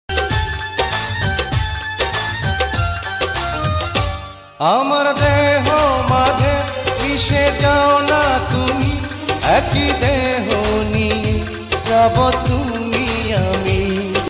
গজল